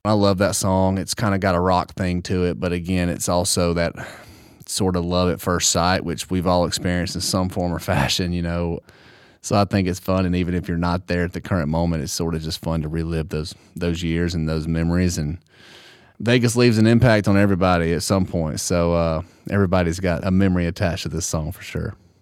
Tyler Hubbard talks about the song, "Vegas," from his forthcoming album Strong.